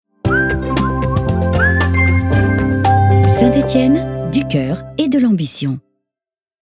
Catégorie : Identité Sonore > Radios